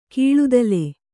♪ kīḷudale